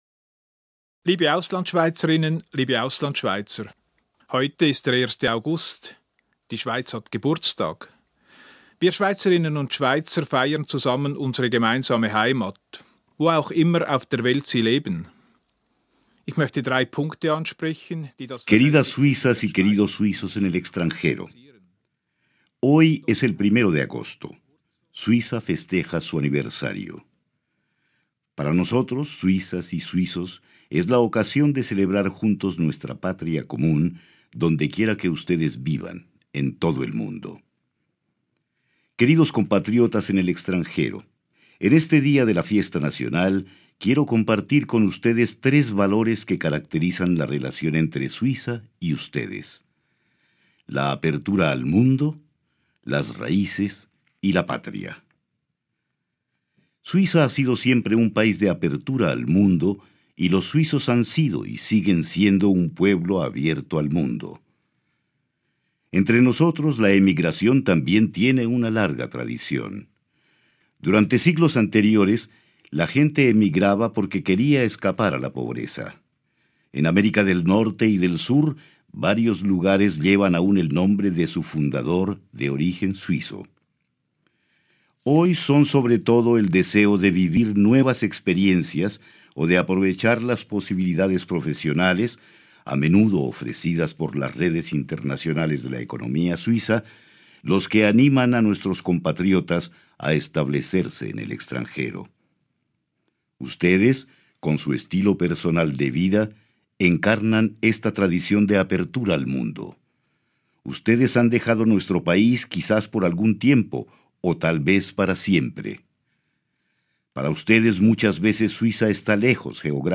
Fiesta Nacional suiza
Discurso del presidente de la Confederación Ueli Maurer a los suizos del exterior.